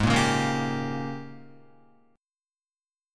G#M7sus2sus4 chord